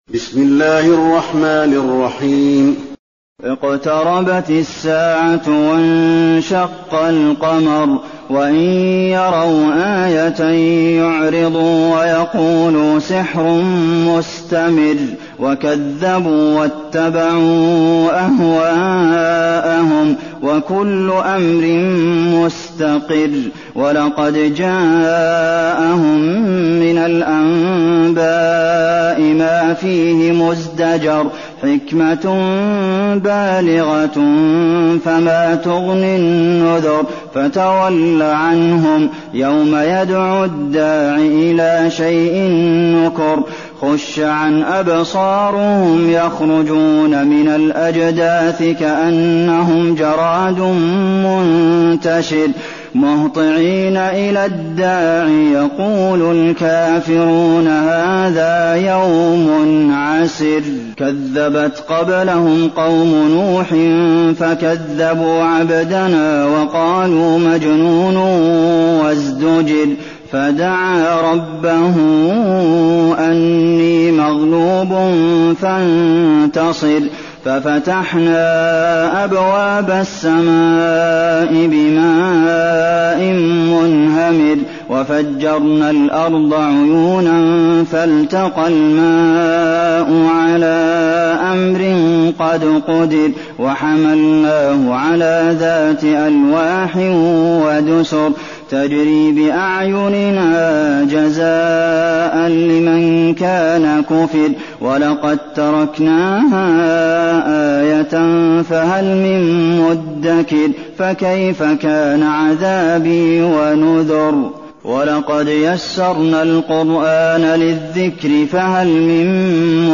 المكان: المسجد النبوي القمر